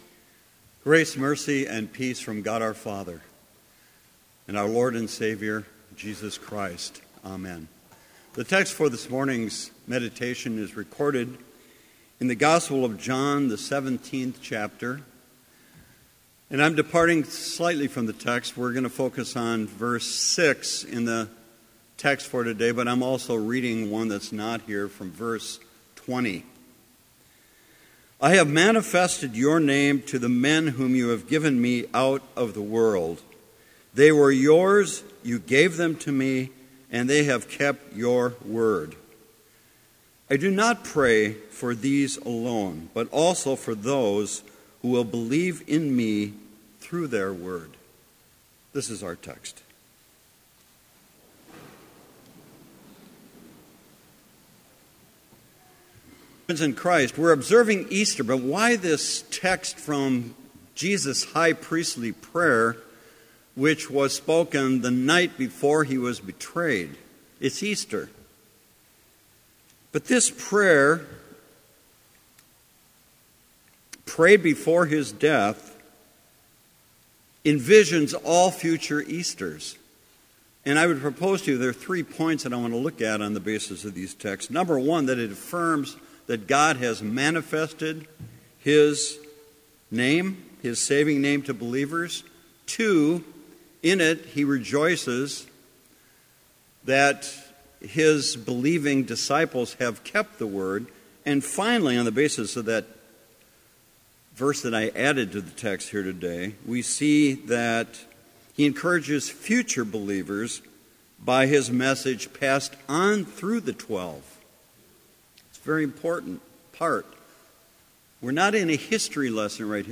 Complete service audio for Chapel - April 19, 2016
Prelude